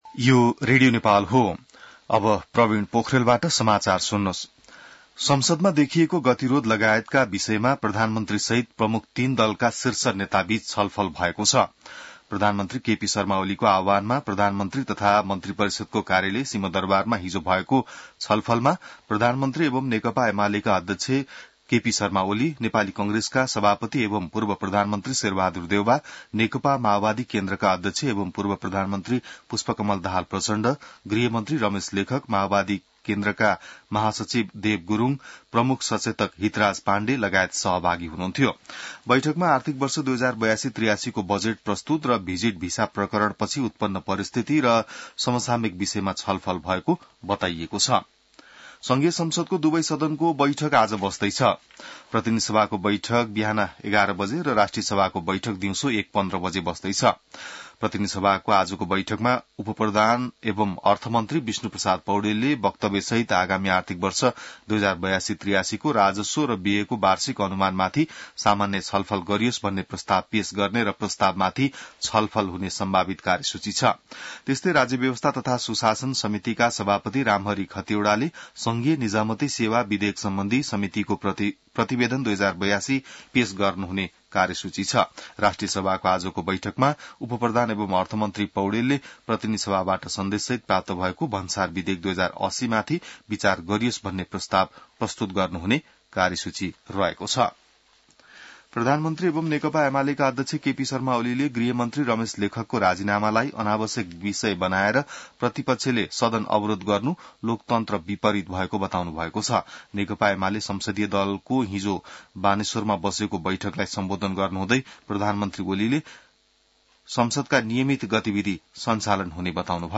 बिहान ६ बजेको नेपाली समाचार : २० जेठ , २०८२